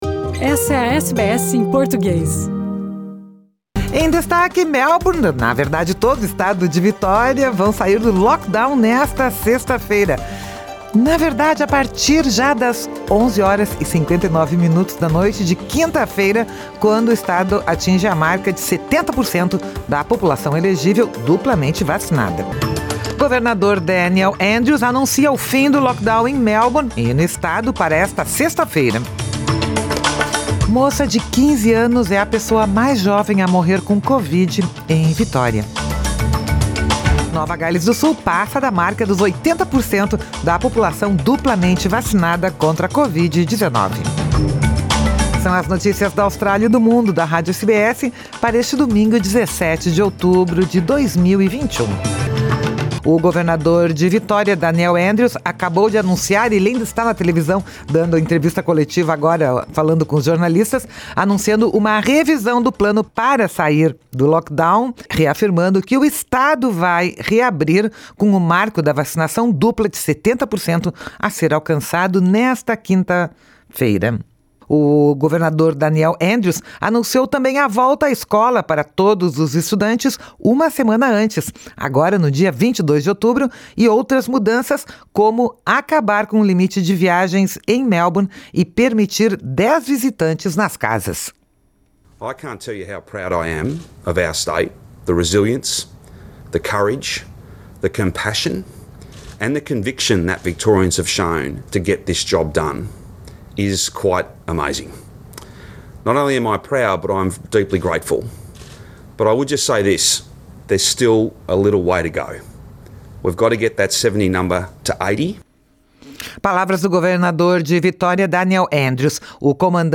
Nova Gales do Sul passa da marca dos 80% da população duplamente vacinada contra a Covid-19. São as notícias da Austrália e do Mundo da Rádio SBS para este domingo, 17 de outubro de 2021.